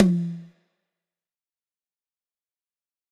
Tom_F1.wav